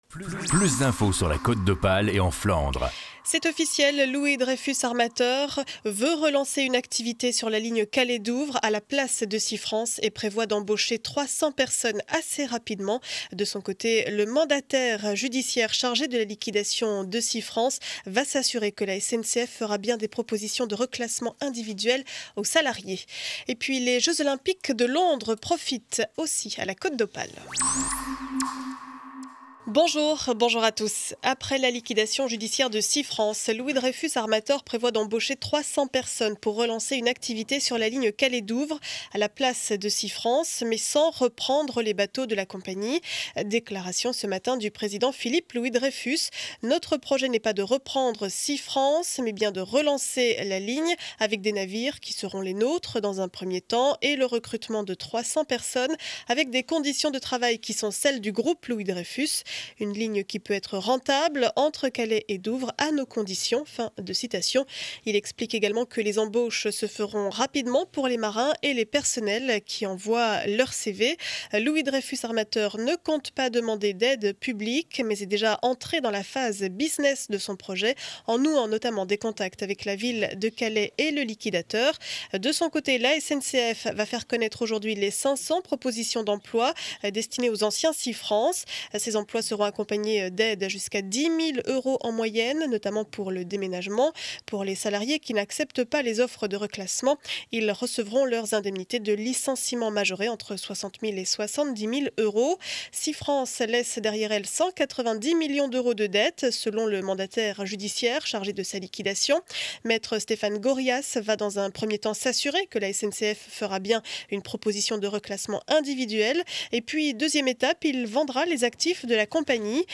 Journal du mercredi 11 janvier 12 heures édition régionale.